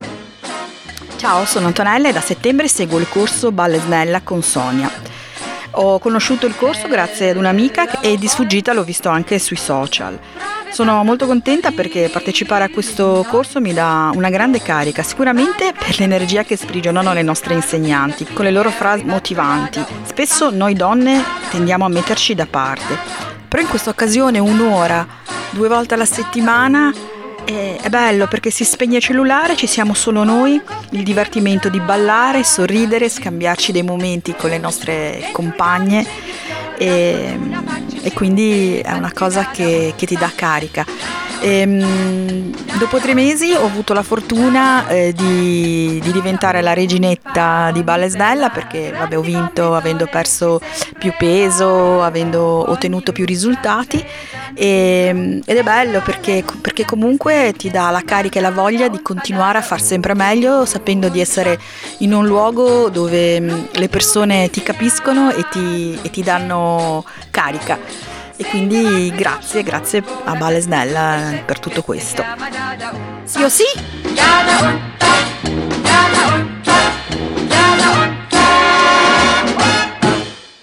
Audio testiomonianza